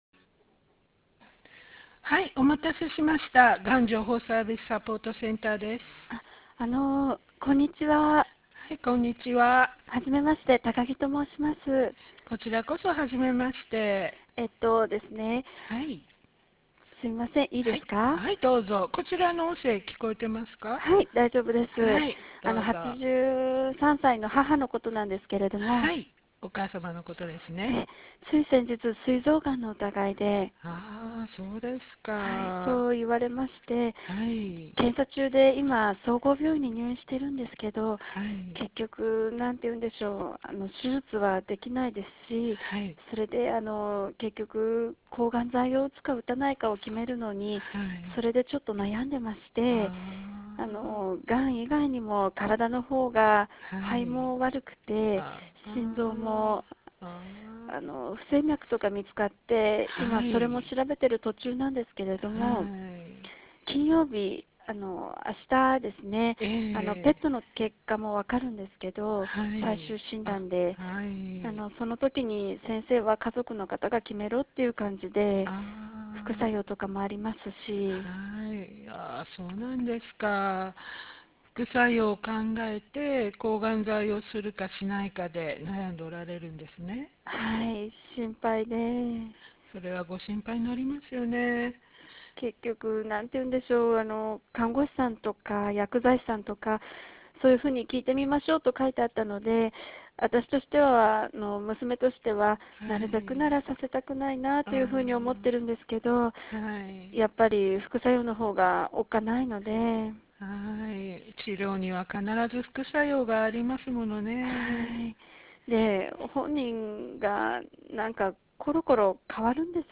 相談音声事例課題（事例4）：[国立がん研究センター がん情報サービス 医療関係者の方へ]
関連情報 事前課題を実施する際の留意点 電話相談事例（事例4）（音声 12分37秒） 電話相談事例逐語録（事例4） がん相談対応評価表 問い合わせ先 本事例を使用して研修を開催される主催者へお問い合わせください。